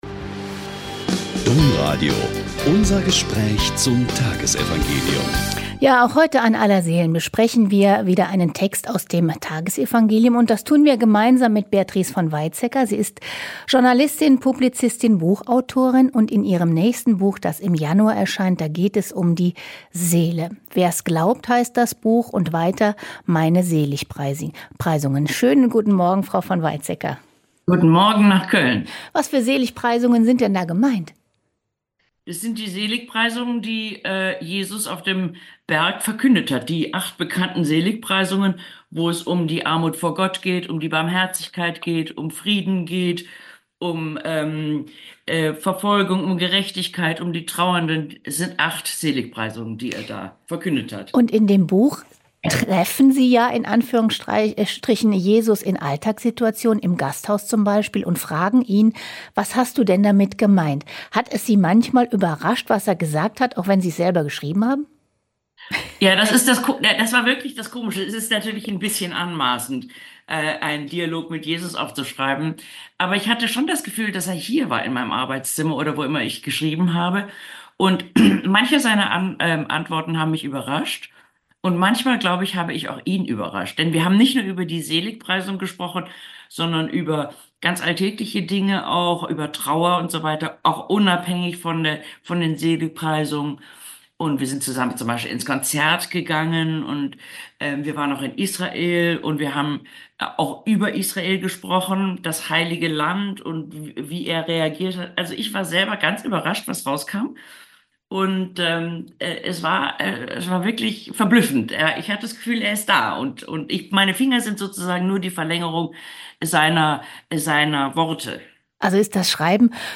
Joh. 6,37-40 - Gespräch